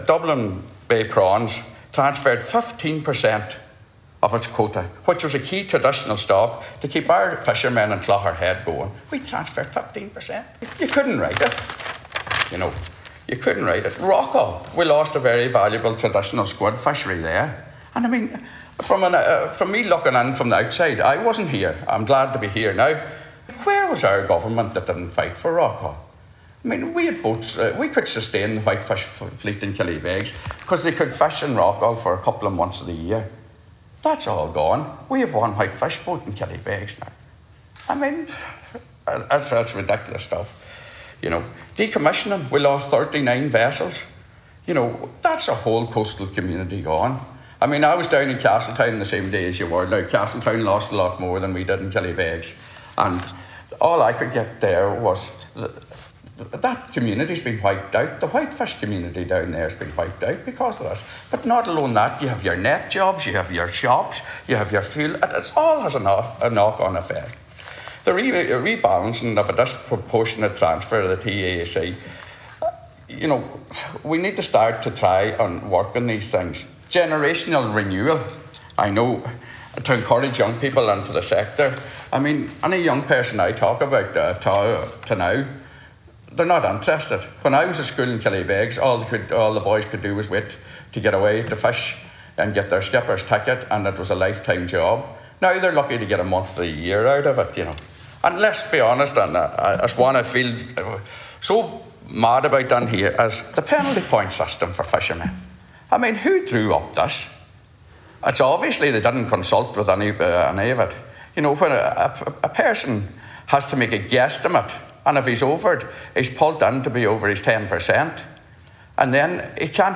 Senator Manus Boyle was speaking during a discussion on the floor of the Seanad about the state of the sea fisheries sector.
You can hear Senator Boyle’s full contribution here –